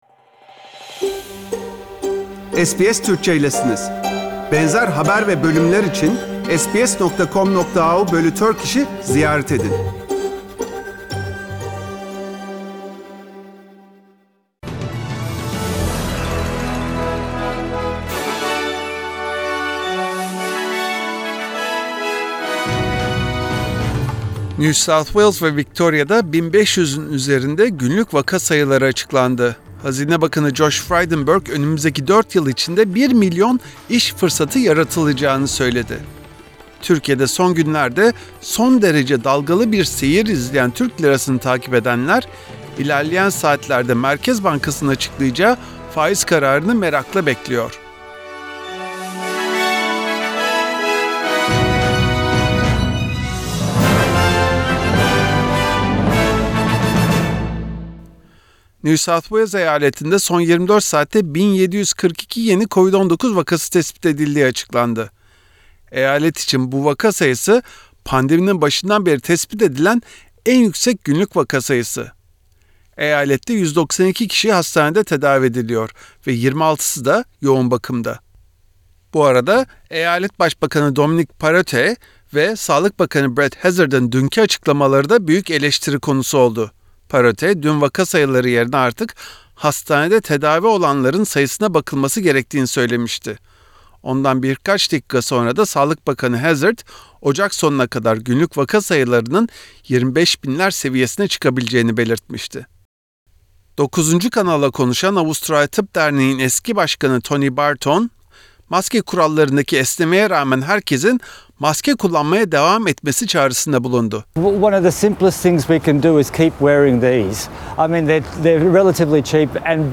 SBS Türkçe Haberler Source: SBS